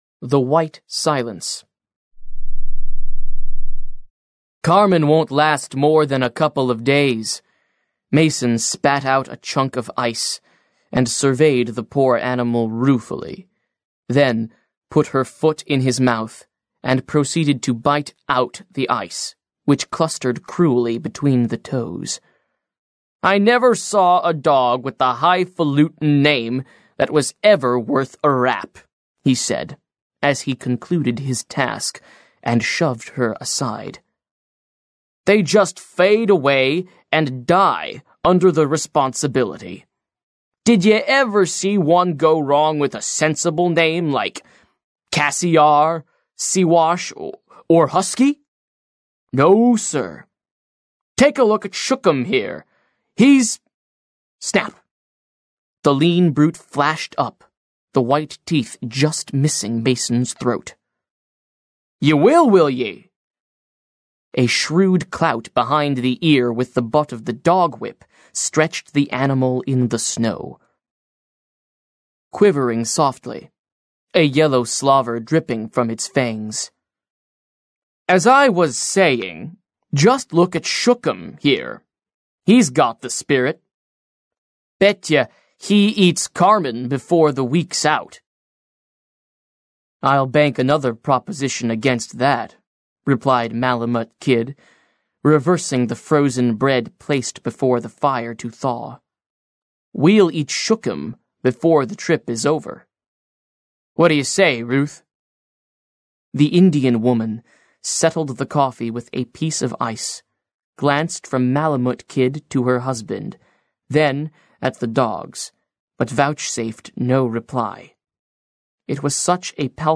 Аудиокнига The Son of the Wolf: Tales of the Far North | Библиотека аудиокниг